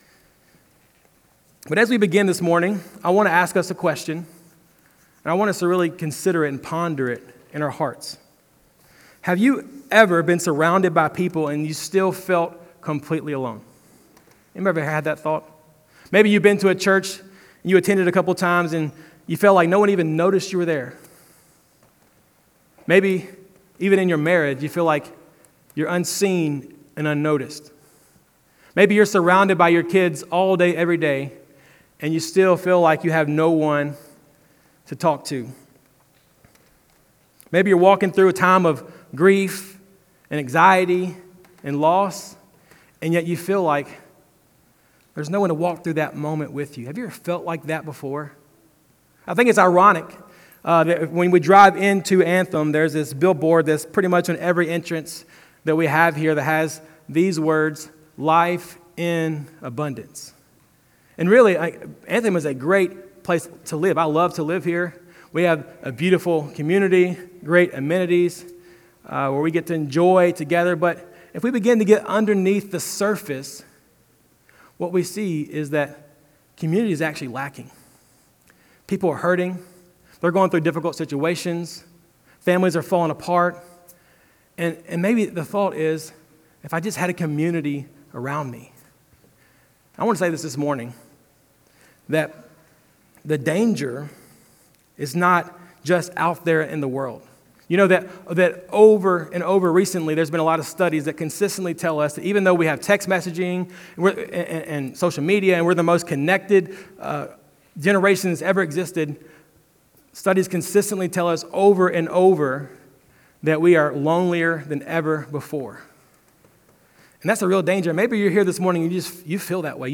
Jan11Sermon.mp3